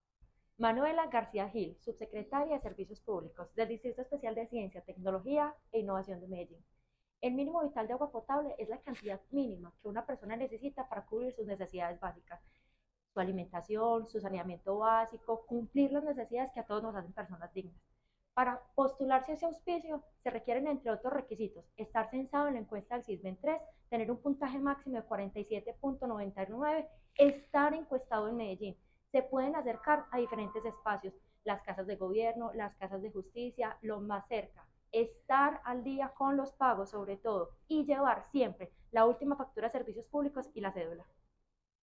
Palabras de habitante del barrio Pedregal